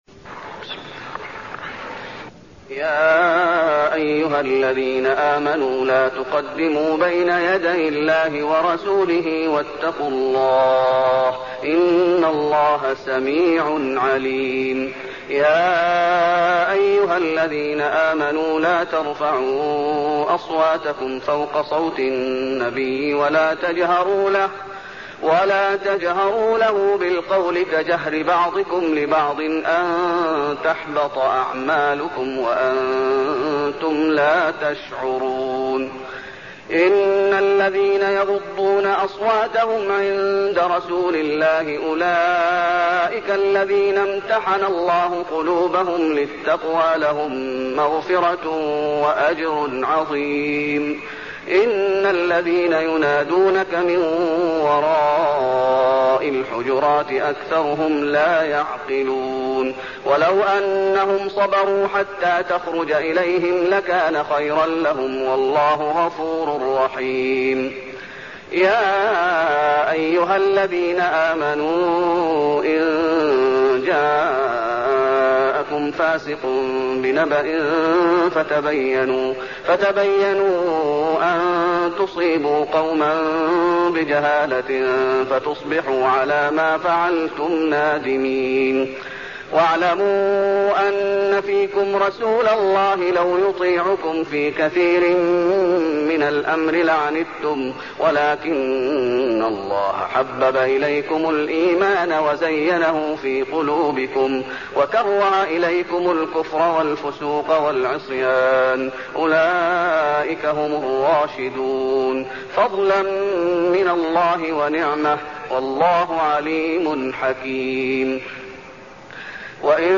المكان: المسجد النبوي الحجرات